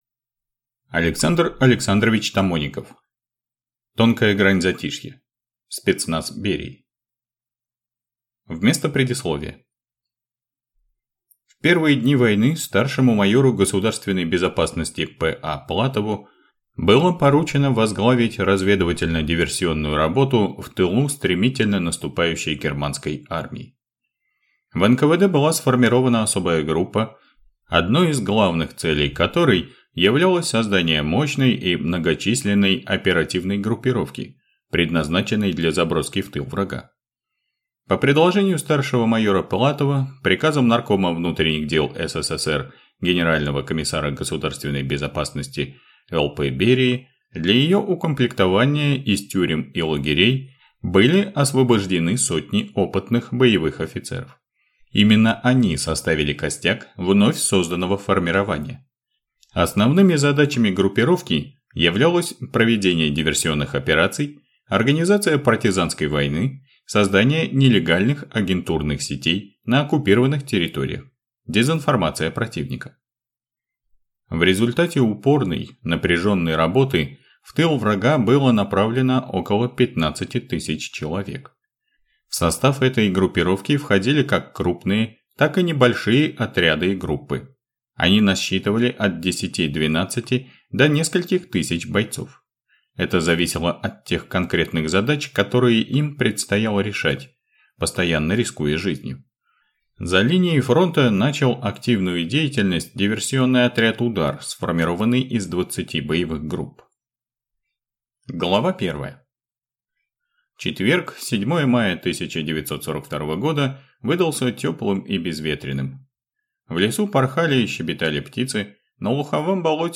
Аудиокнига Тонкая грань затишья | Библиотека аудиокниг